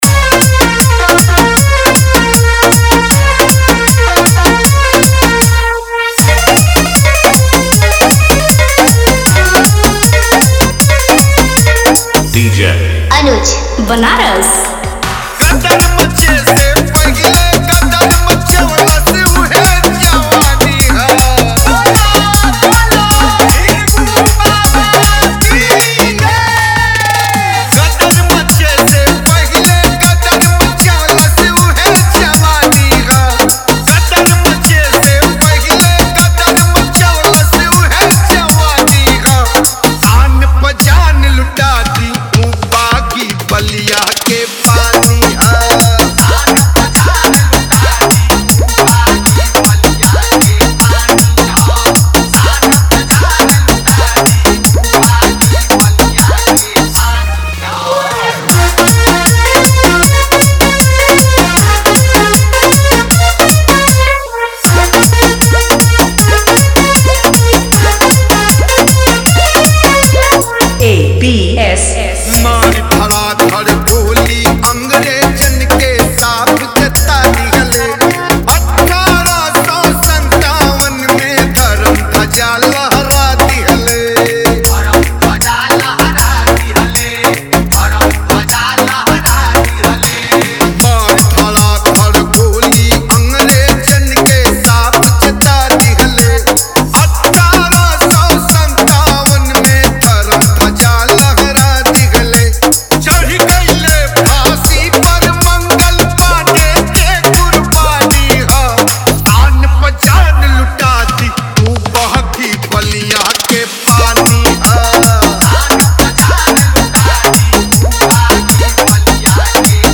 EDM BASS MIX